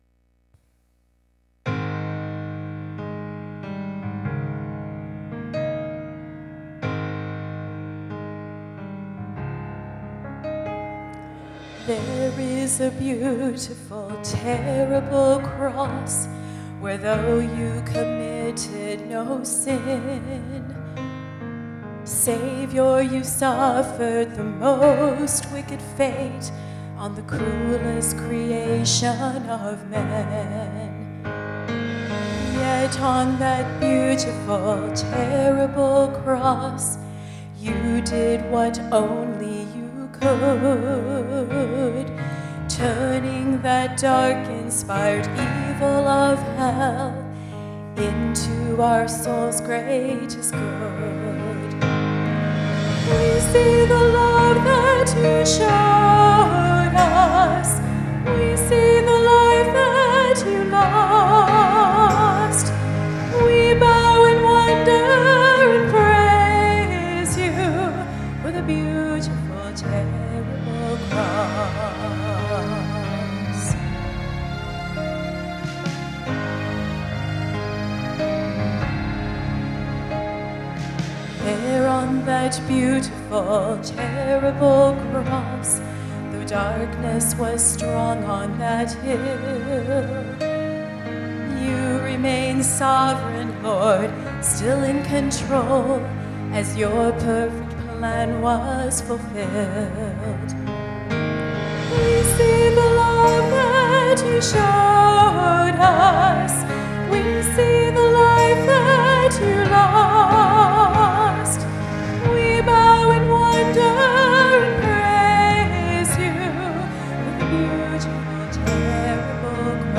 Sunday Morning Music